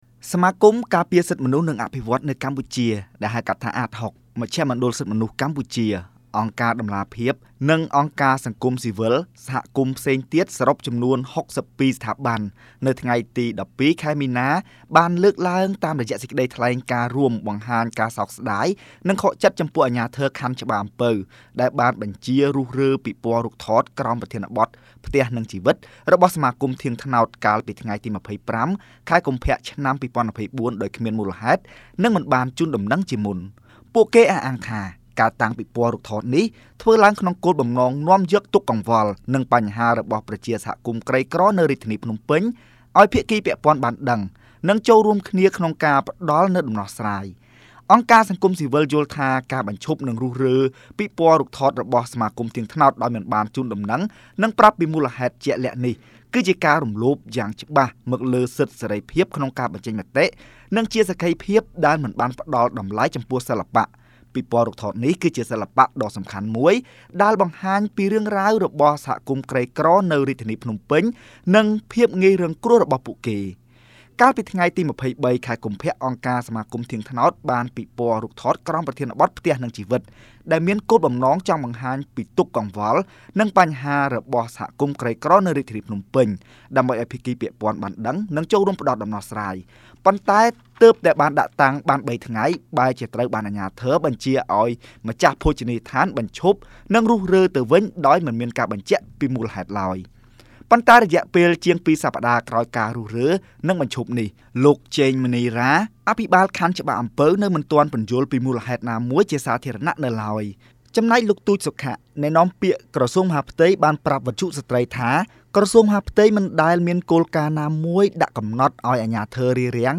Radio
លោក ទូច សុខៈ អ្នកនាំពាក្យក្រសួងមហាផ្ទៃ